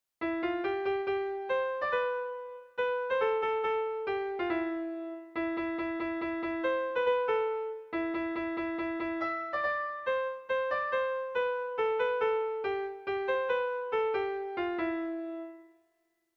Irrizkoa
Kopla handiaren moldekoa
ABD